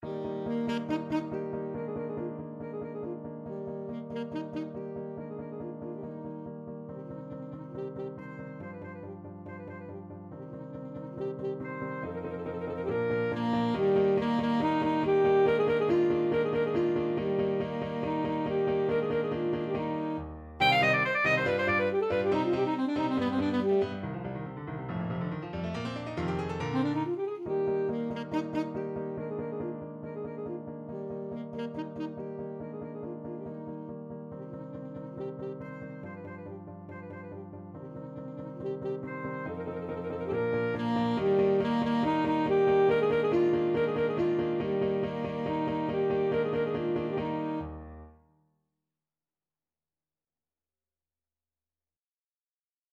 Classical Beethoven, Ludwig van Rondo a capriccio, Op.129 (Rage over a lost penny) (Main Theme) Alto Saxophone version
Eb major (Sounding Pitch) C major (Alto Saxophone in Eb) (View more Eb major Music for Saxophone )
~ = 140 Allegro vivace (View more music marked Allegro)
2/4 (View more 2/4 Music)
Classical (View more Classical Saxophone Music)
rondo-a-capriccio-op-129_ASAX.mp3